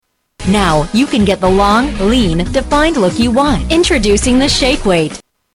Tags: Shake Weight for Women Shake Weight for Women clips Shake Weight for Women commercial Shake Weight Shake Weight clips